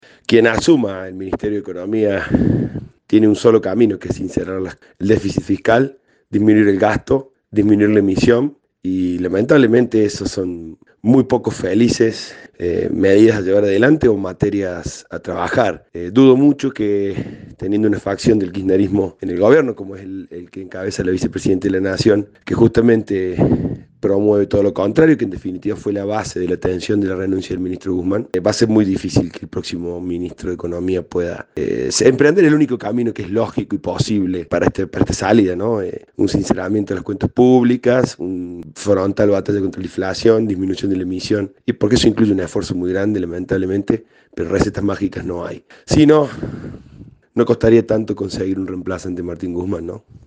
En diálogo con EL CAMPO HOY, distintos referentes del agro cordobés y nacional, se mostraron escépticos por la  renuncia de Martín Guzmán y la designación de Silvina Batakis en la cartera de Economía.